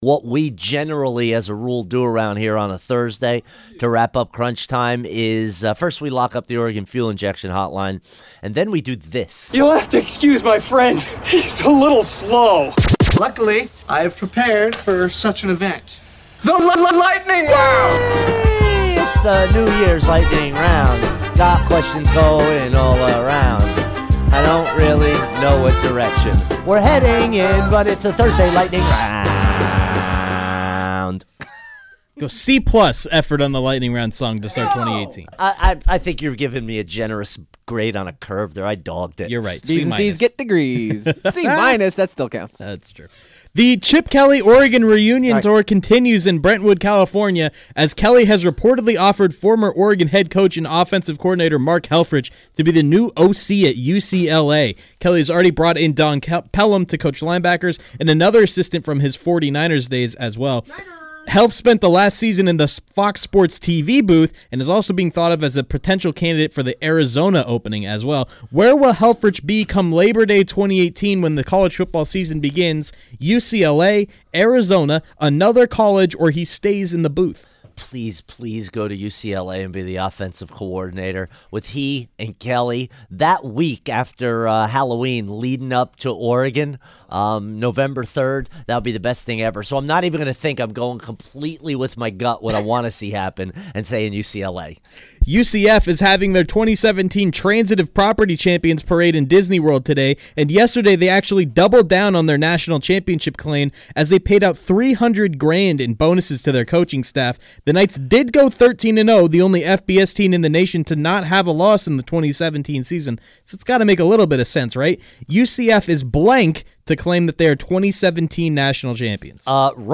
rapid fire style